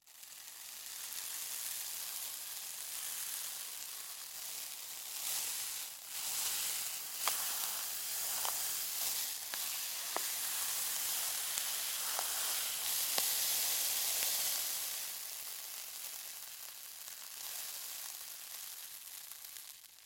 На этой странице собраны разнообразные звуки песка: от шуршания под ногами до шелеста дюн на ветру.
Песок медленно сыпется